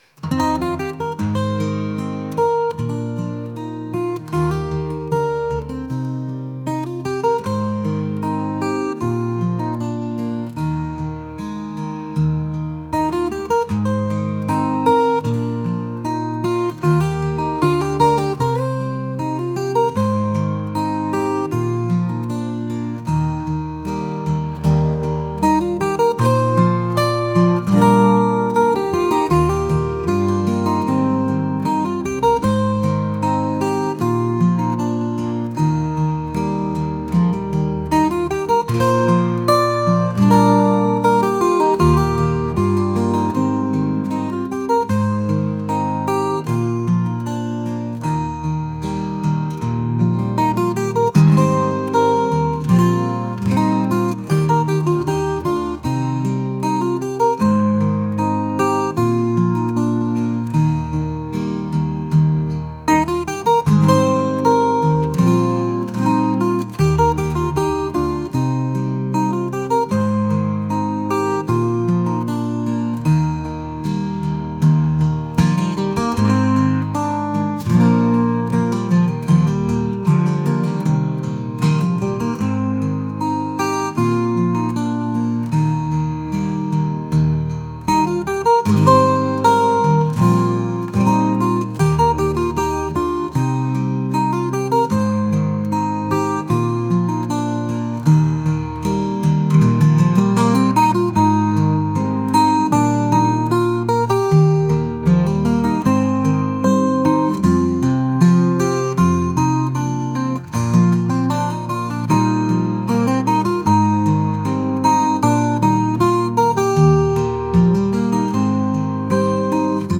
acoustic | folk | pop